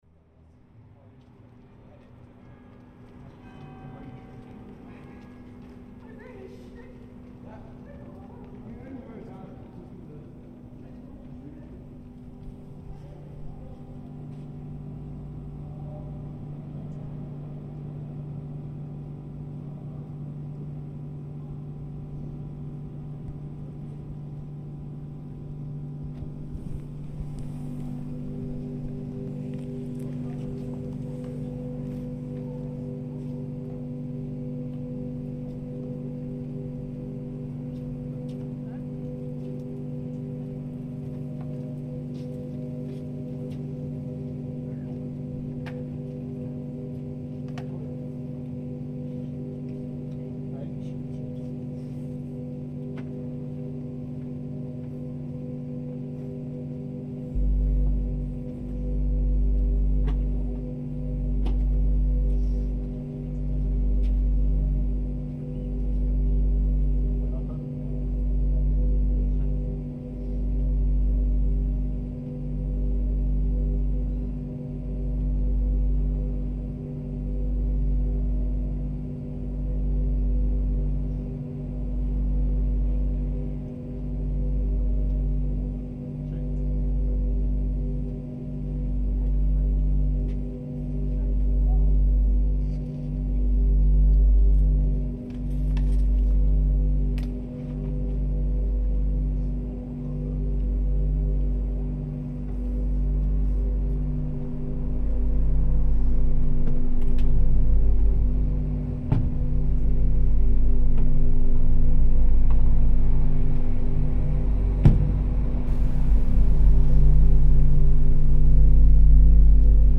Lockdown hum in Oxford reimagined